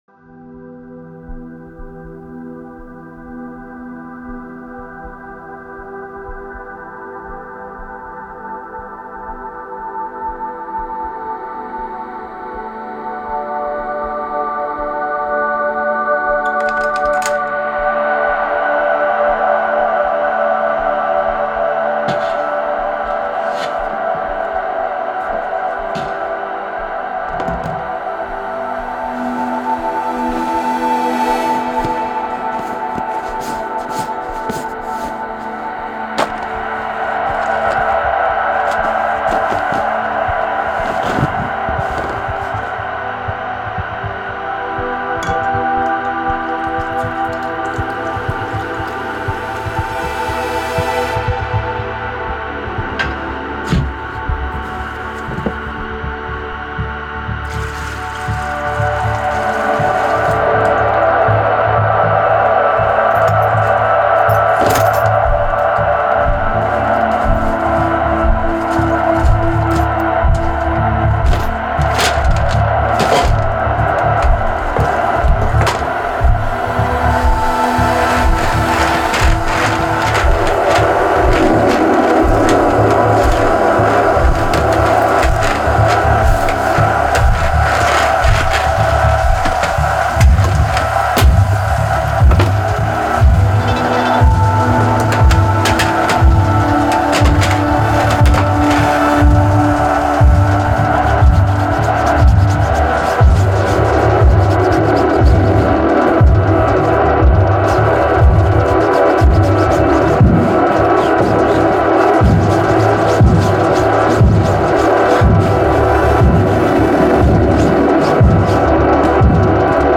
He mixes electronic and organic instruments to craft unique musical universes.
J’ai donc fait deux essais différents où j’utilise la même palette de sons mais de manière plus hybride.